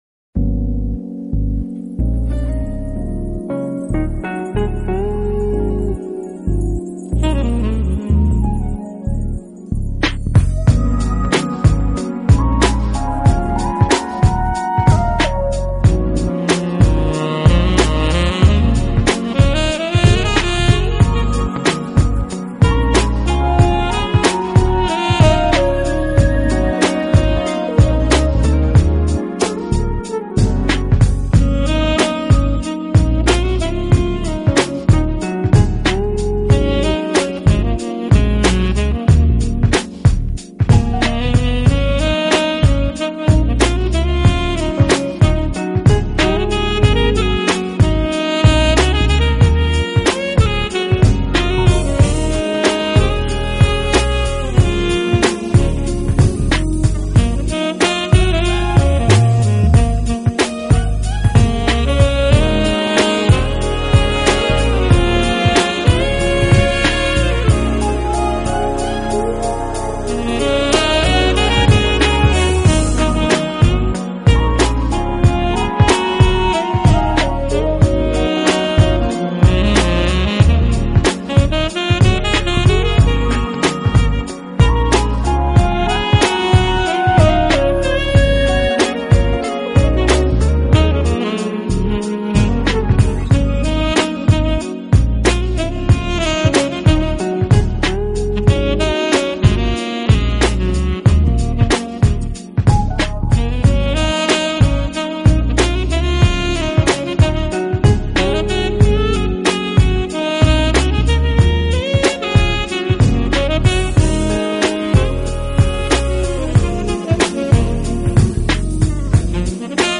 【爵士萨克斯】
风格偏于布鲁斯和流行爵士。